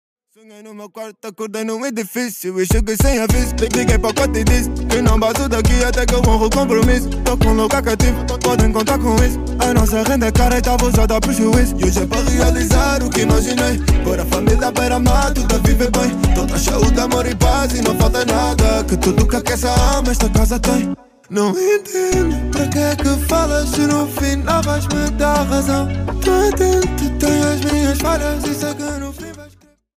Hip-Hop / Urban